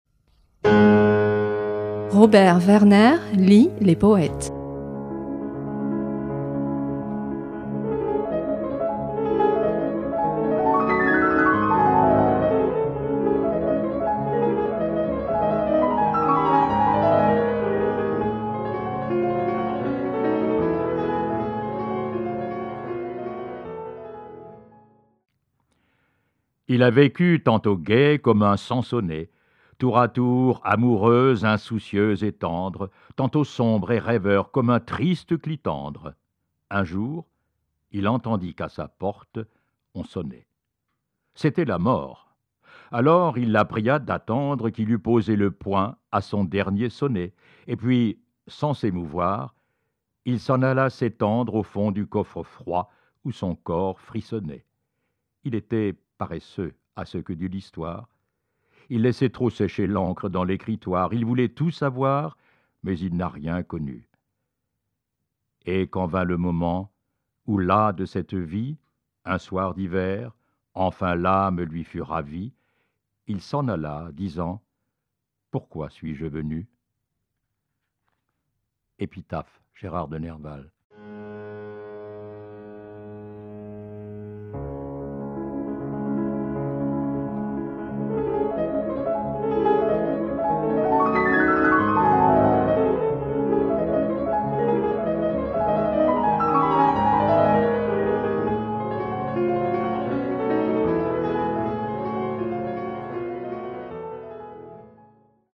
À voix lue
Lecture